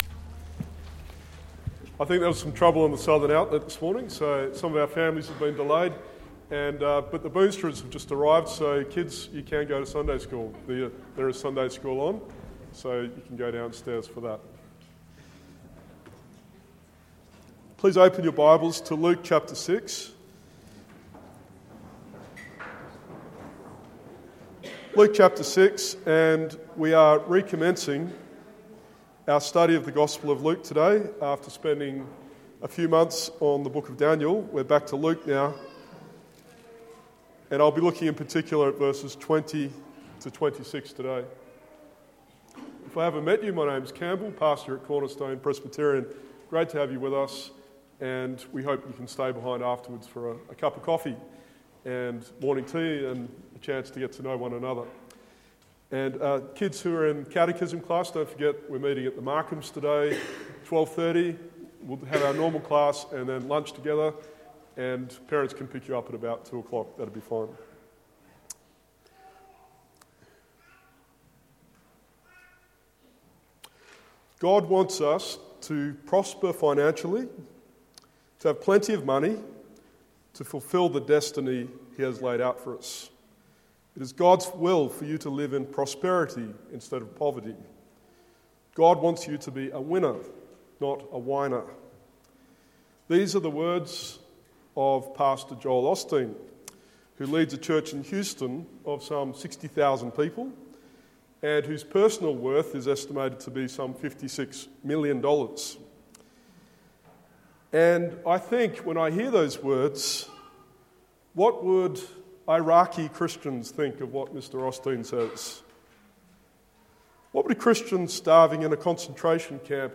Luke 6:1-26 Sermon